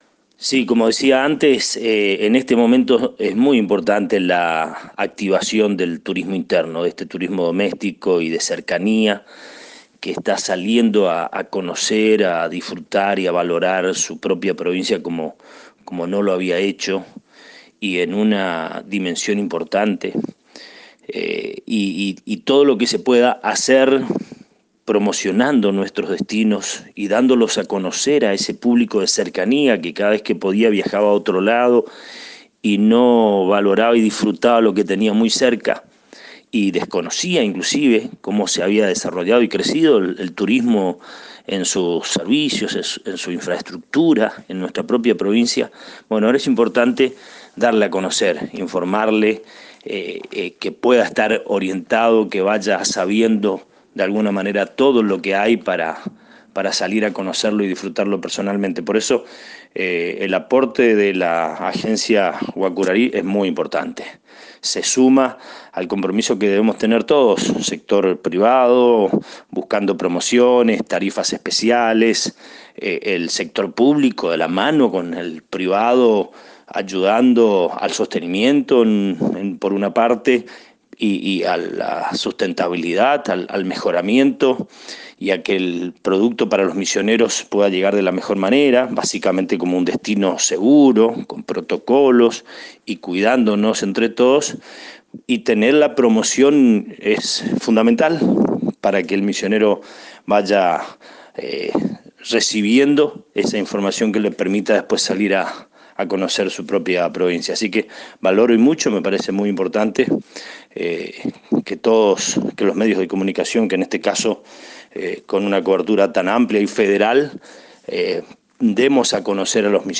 El Subsecretario de Turismo de la Provincia Carlos Antonio»Tony» Lindstrom, dialogó con la Cadena de Radios Guacurarí.